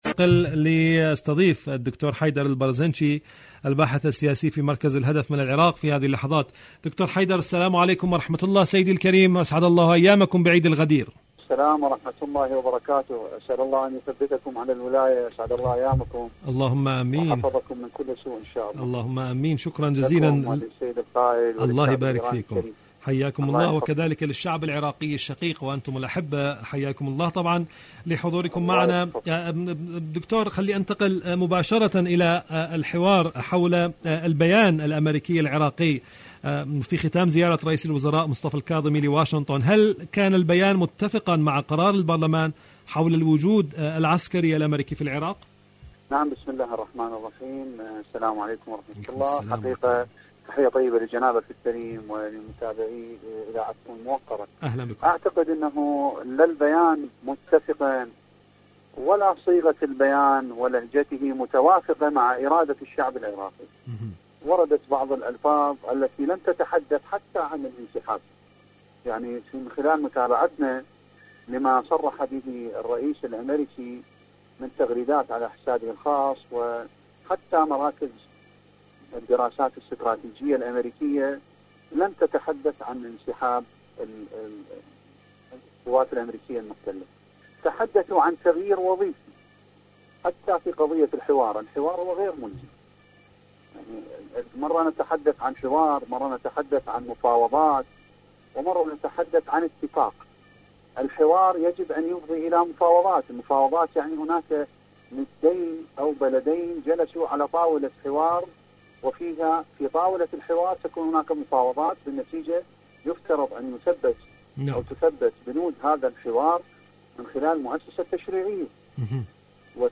إذاعة طهران-حدث وحوار: مقابلة إذاعية